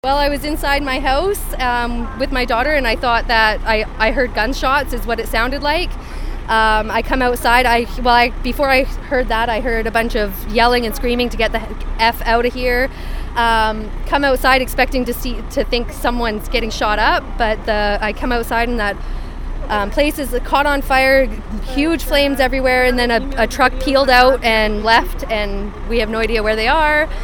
Goat News was on scene and spoke to a witness who lives just across the street.